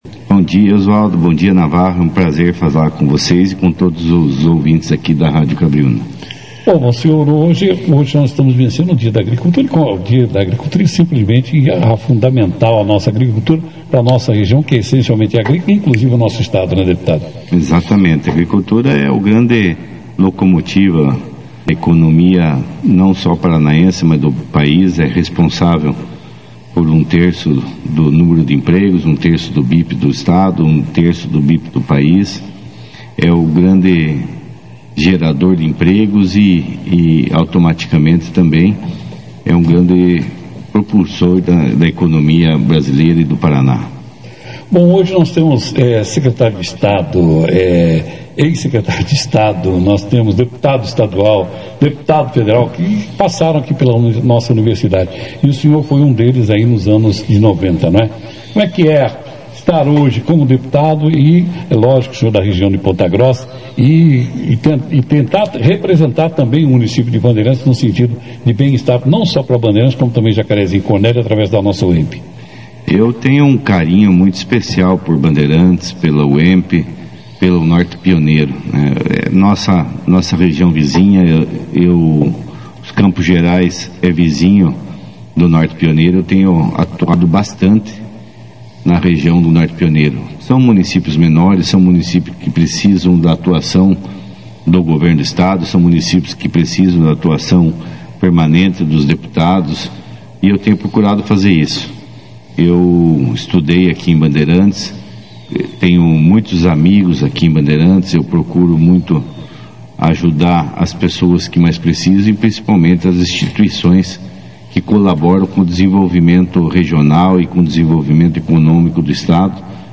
Em entrevista ao vivo na 2ª edição do Jornal Operação Cidade, Fadel destacou a satisfação em retornar ao campus onde se formou engenheiro agrônomo, relembrando sua trajetória acadêmica.